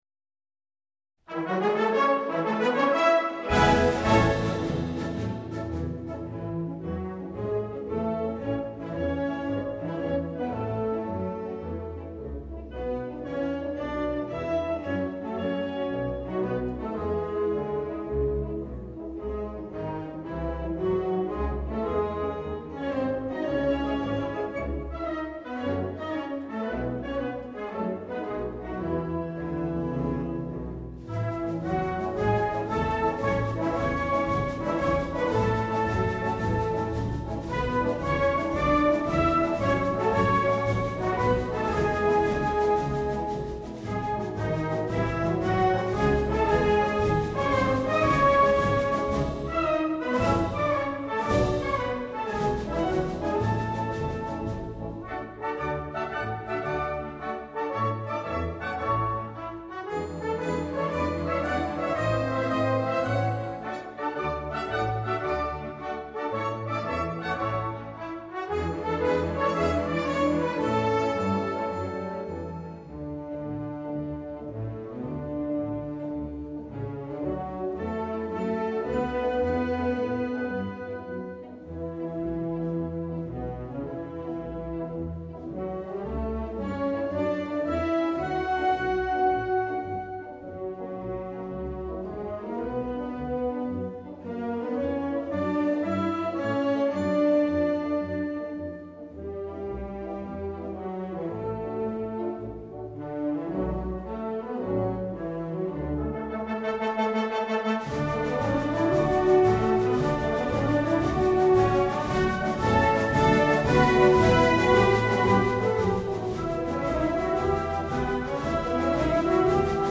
alsaciana. marcha banda madrid. 1995.mp3